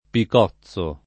picozzo [ pik 0ZZ o ] o piccozzo s. m.